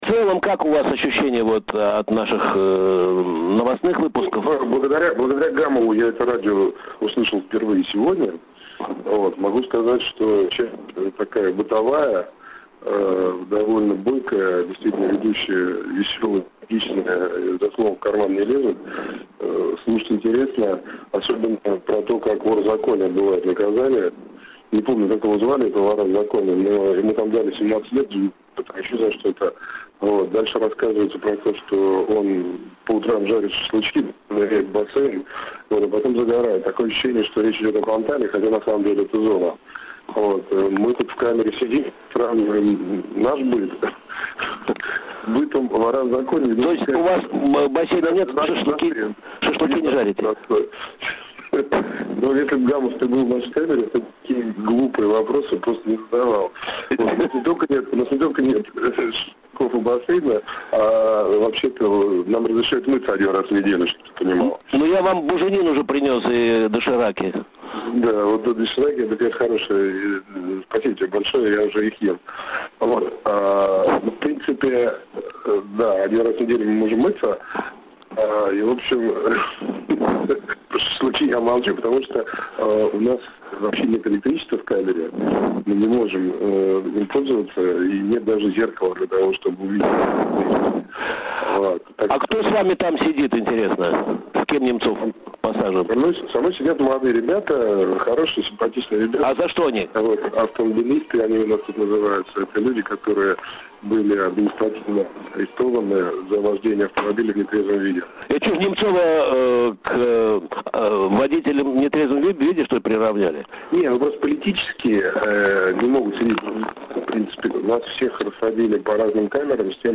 История. Интервью с Борисом Немцовым
Вчера — за несколько часов до выходы Немцова на свободу — мы позвонили ему в камеру…
Фрагмент передачи Радио «КП» за 6 марта 2014 года